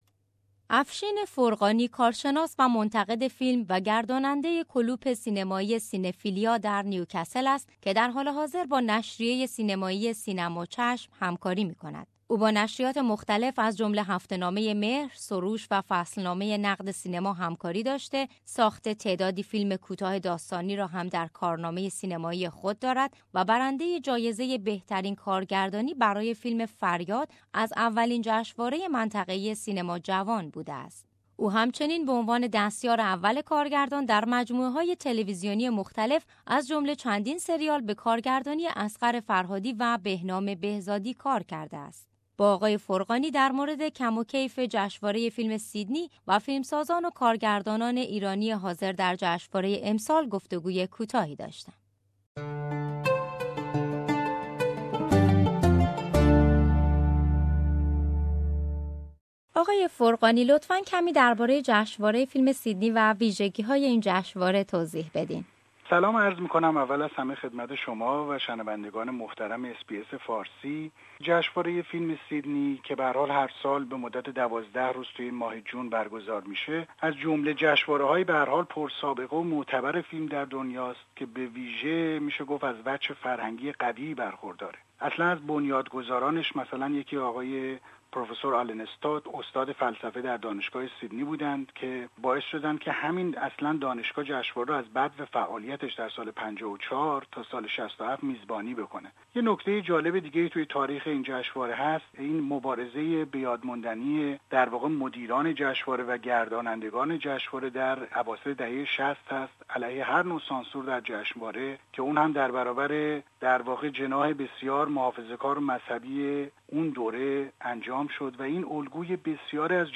در مورد کم و کیف جشنواره فیلم سیدنی و فیلمسازان و کارگردانان ایرانی حاضر در جشنواره امسال گفت و گوی کوتاهی داشتم.